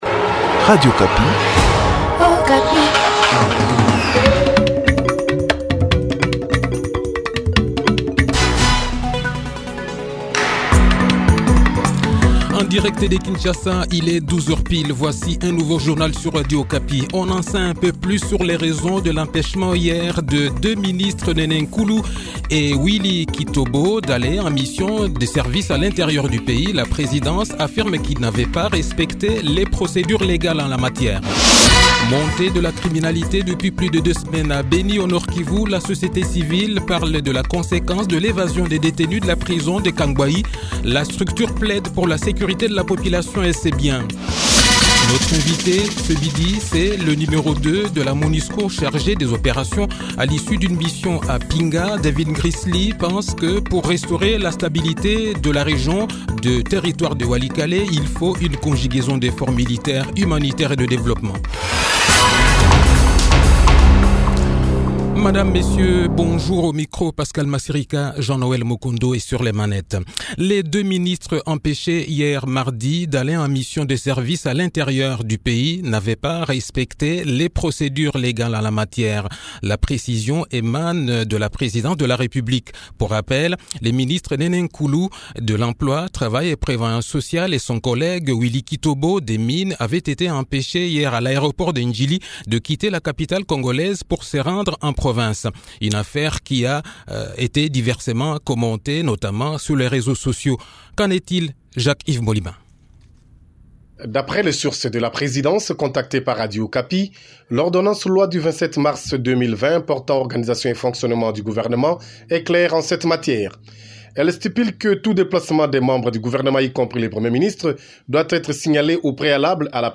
Le journal de 12 h, 11 Novembre 2020